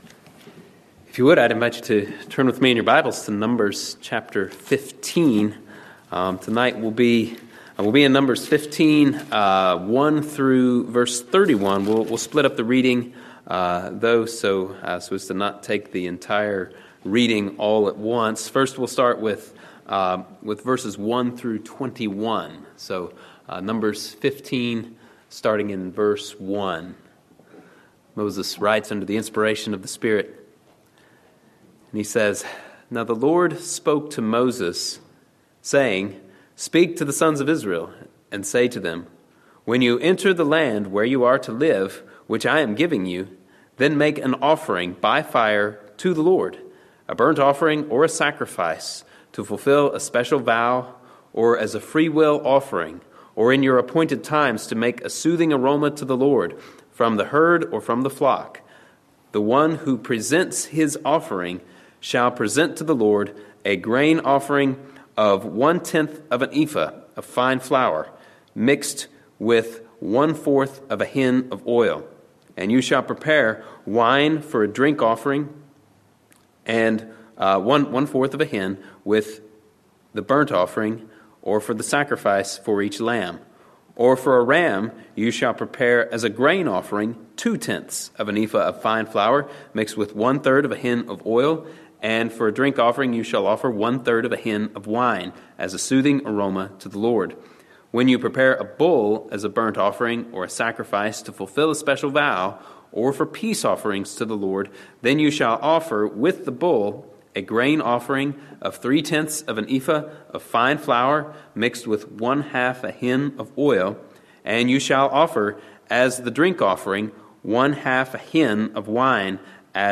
Sermons from Andover Baptist Church in Linthicum, MD.